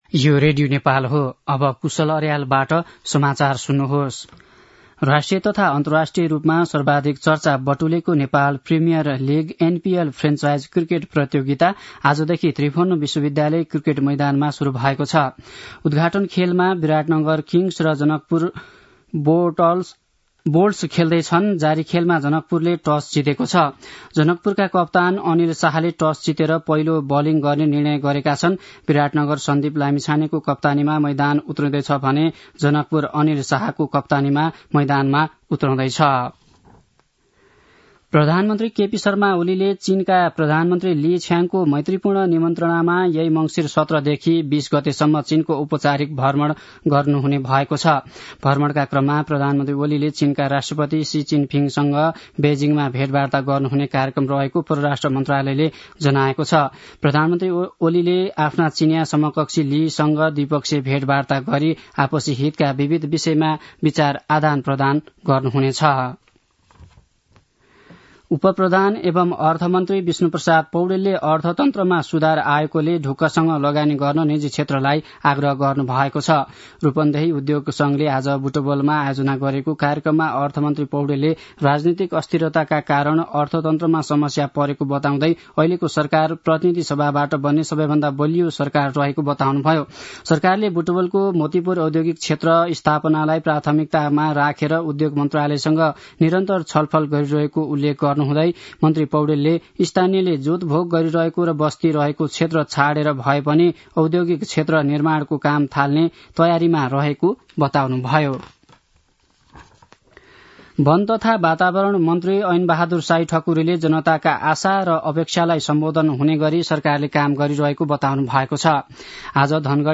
दिउँसो १ बजेको नेपाली समाचार : १६ मंसिर , २०८१
1-pm-nepali-news-1-12.mp3